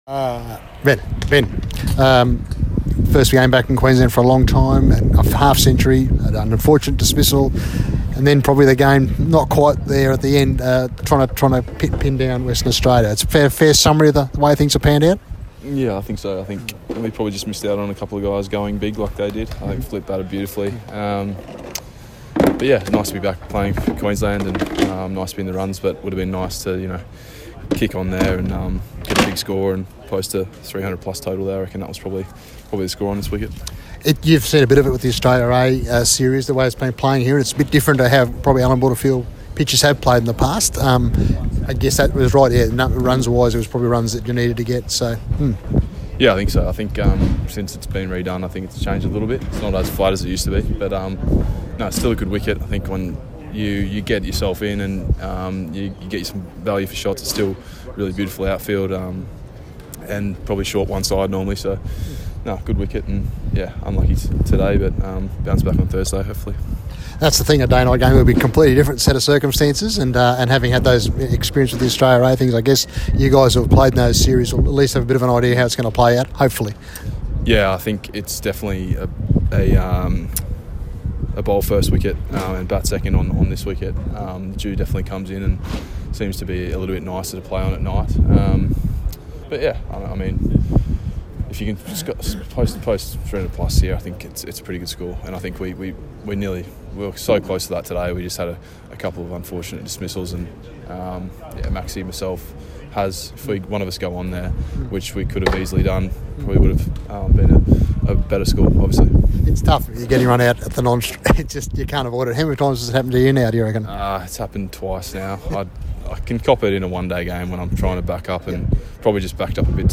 Interview with Queensland batsman Ben McDermott following Western Australia’s four wicket win over Queensland